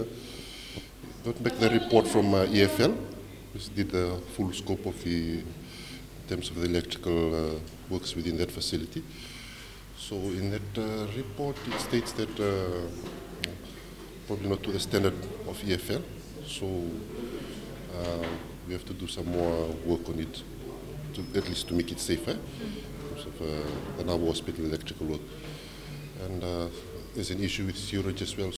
Health Minister, Doctor Atonio Lalabalavu.